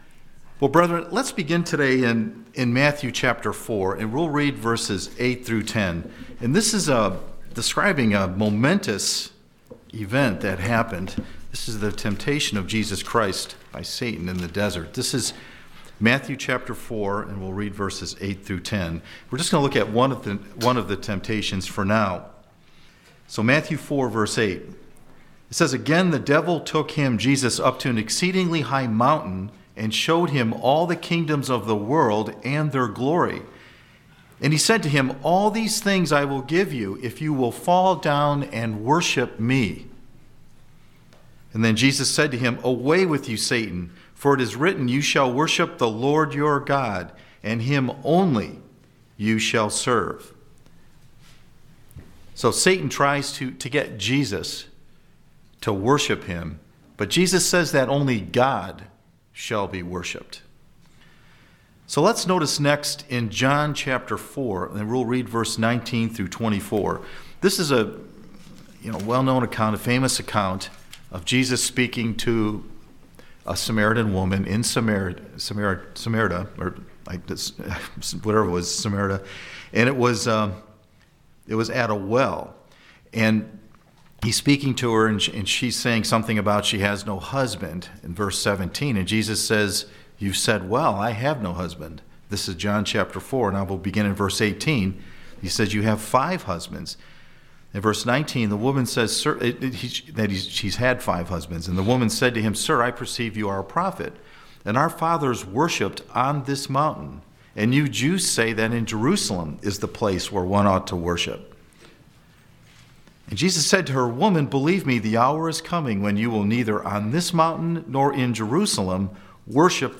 What does it mean to worship in spirit and truth? This sermon examines the true worship of God and how it fits in with Christmas.